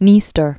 (nēstər, dnyĕ-) or Dni·stro (nēstrō, dnyē-)